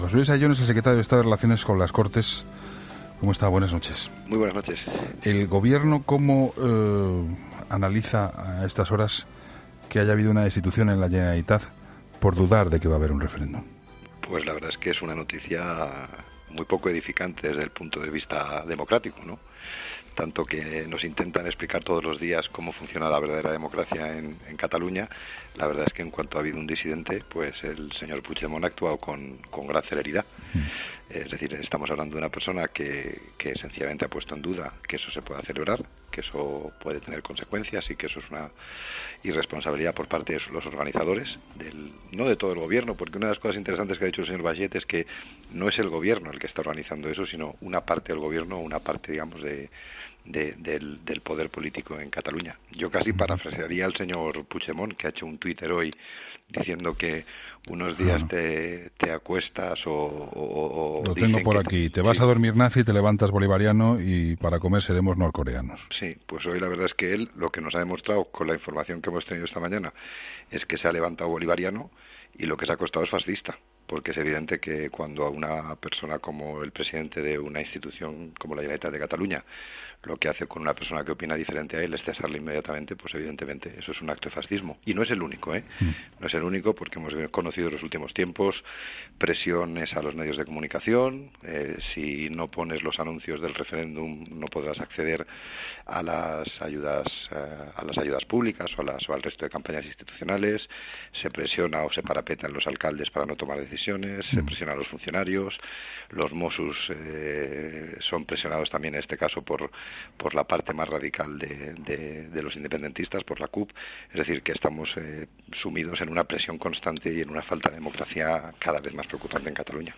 ESCUCHA LA ENTREVISTA COMPLETA | José Luis Ayllón en 'La Linterna' “ Puigdemont se ha levantado bolivariano y se ha acostado fascista.